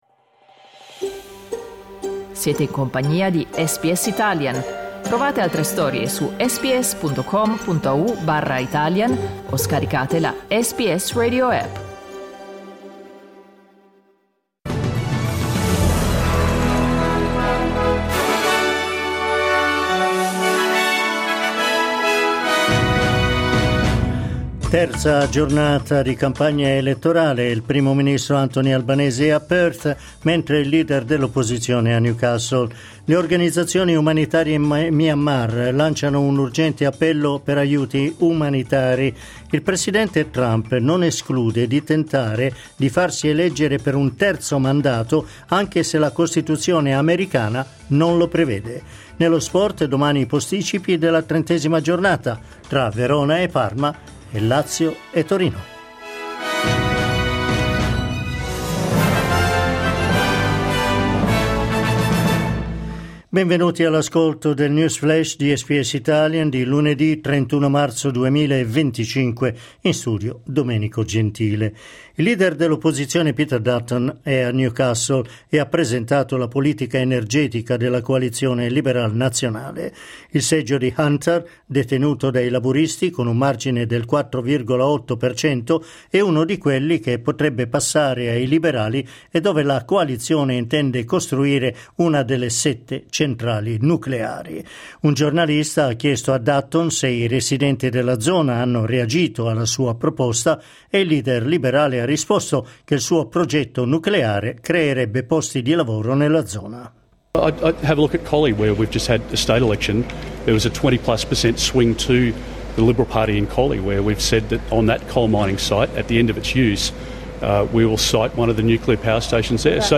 News flash lunedì 31 marzo 2025
L’aggiornamento delle notizie di SBS Italian.